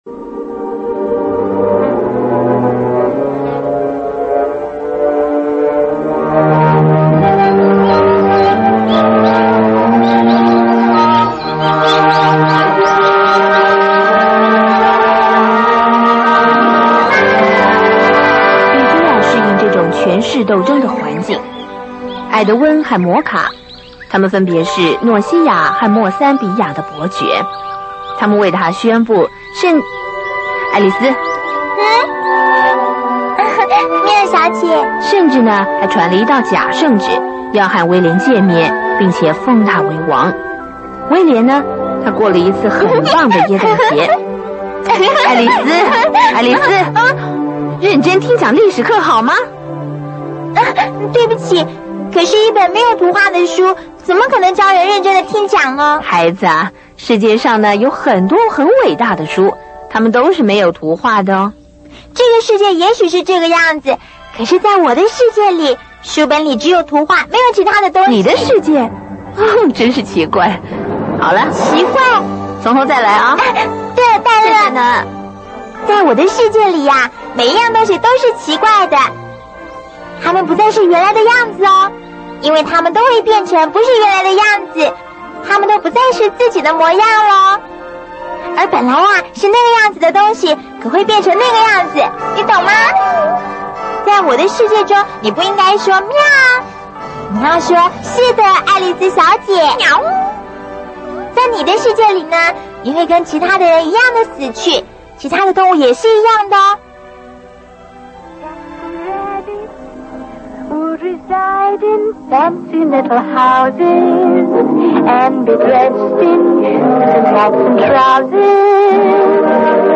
[28/5/2009]（六一节小礼物）1951年迪斯尼经典动画片《爱丽丝梦游仙境》电影录音
声音： 单声道